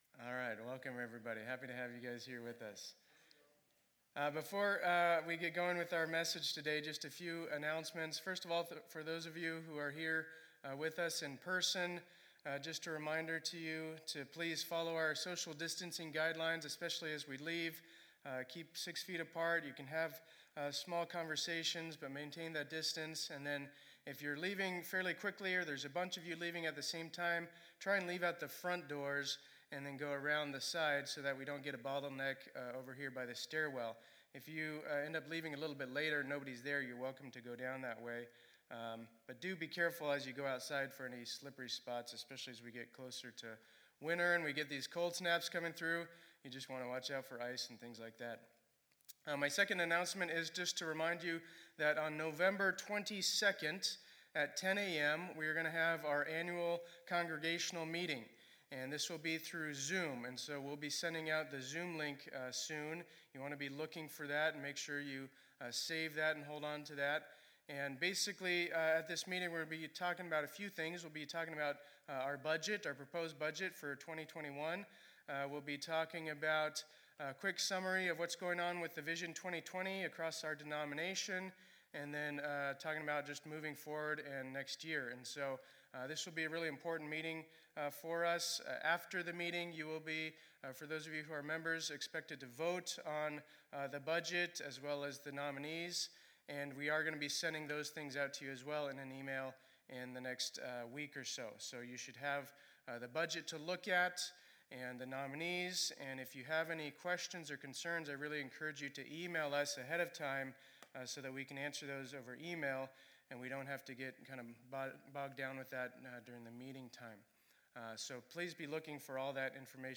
2020-10-25 Sunday Service